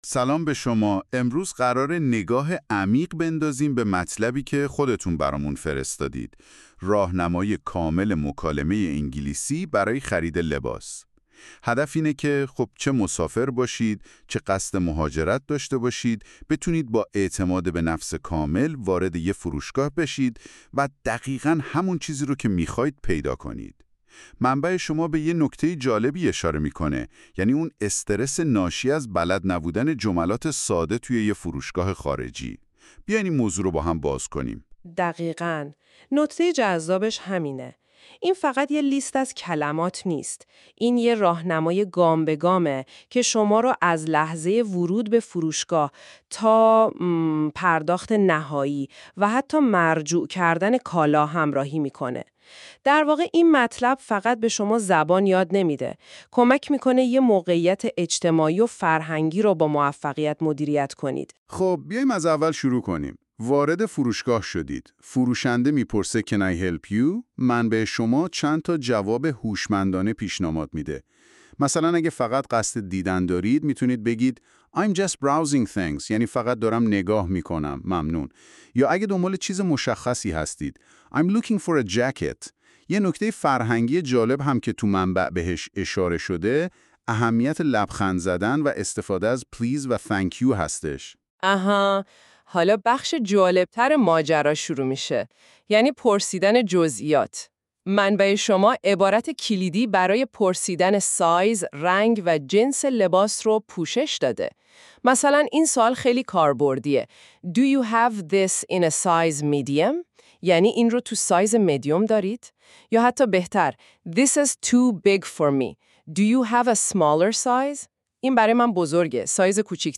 clothes-shopping-conversation.mp3